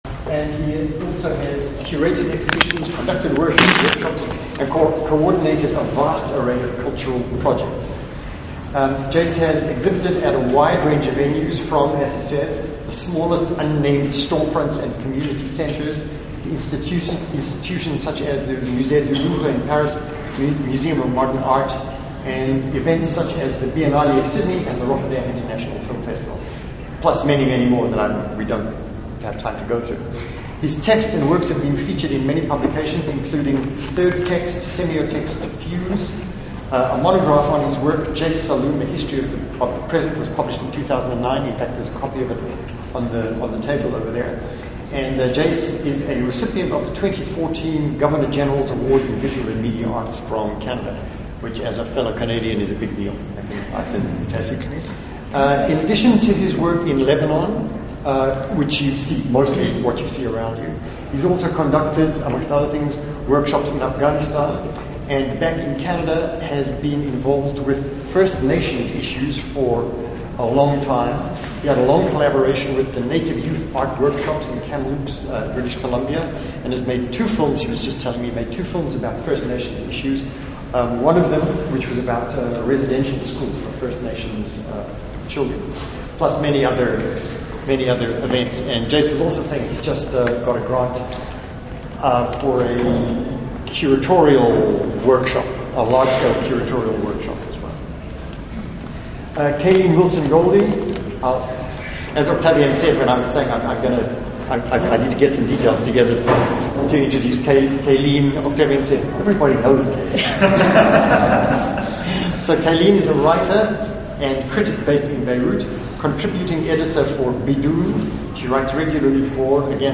Recording of Gallery talk
in AUB Byblos Bank Art Gallery